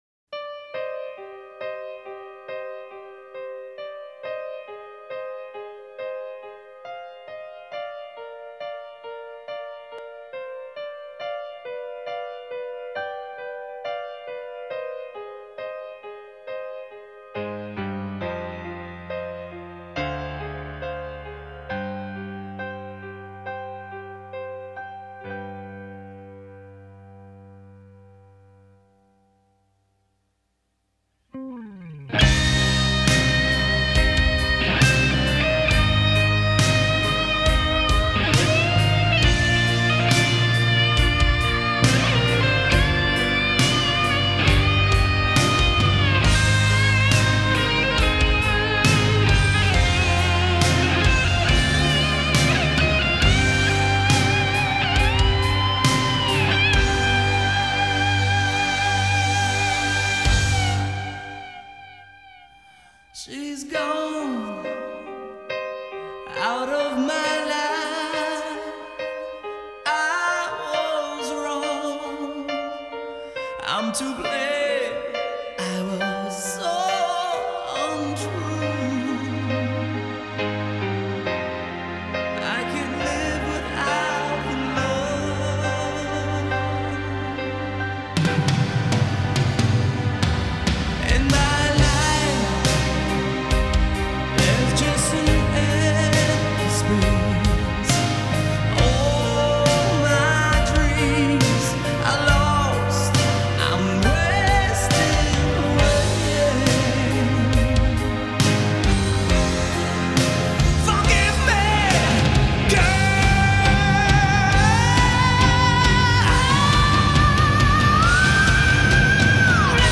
سبک راک
یک گروه راک و متال آمریکایی